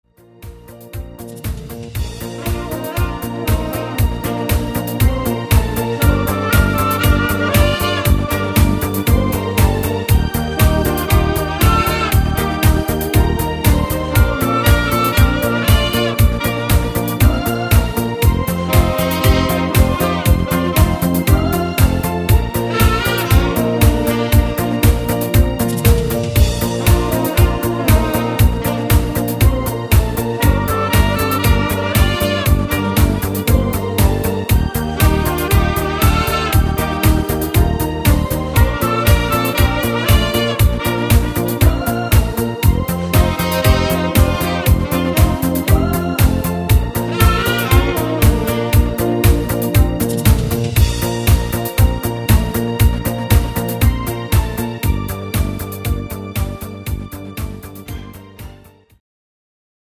Discofox on Sax